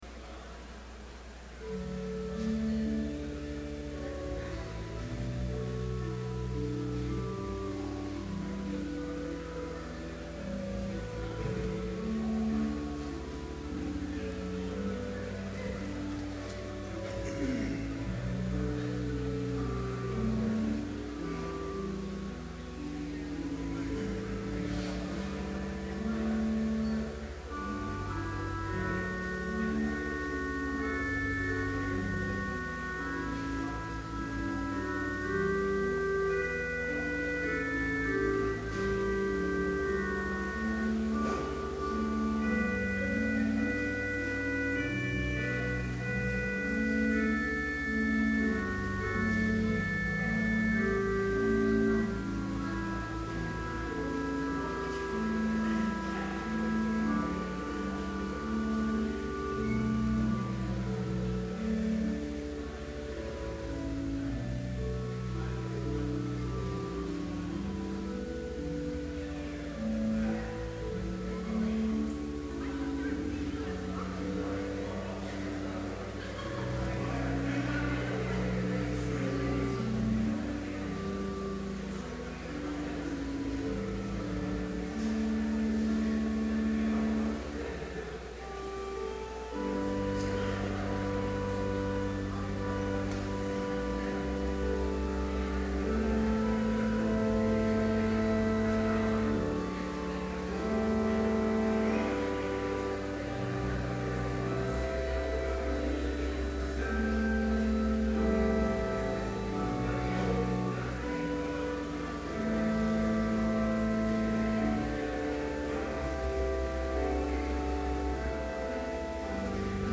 Complete service audio for Chapel - January 17, 2012